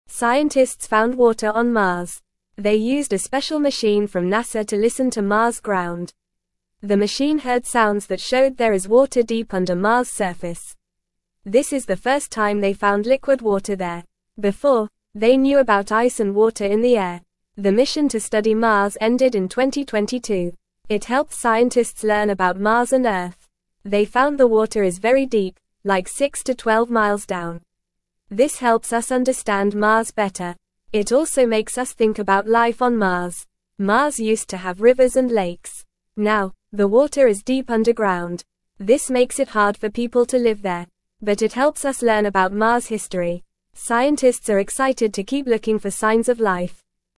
Fast
English-Newsroom-Beginner-FAST-Reading-Water-Found-on-Mars-Deep-Underground-Excites-Scientists.mp3